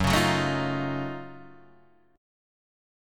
F#mM13 chord {2 4 4 2 4 1} chord